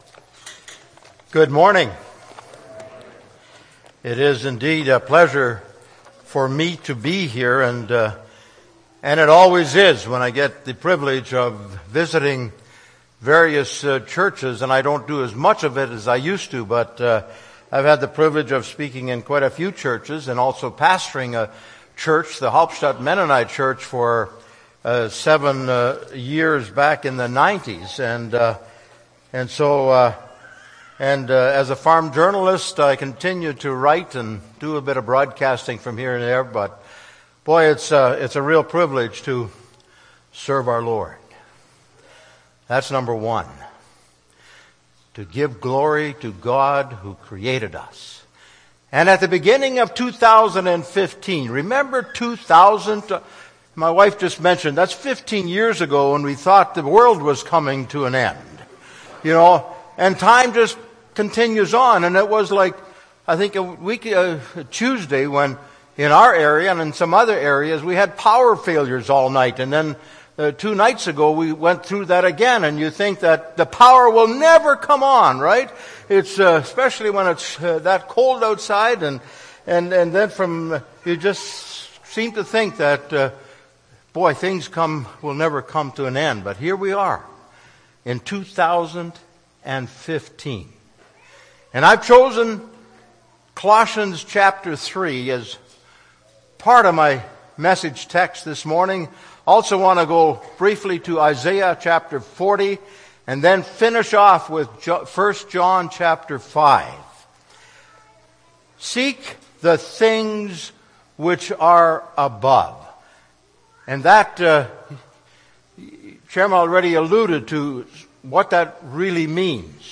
Jan. 4, 2015 – Sermon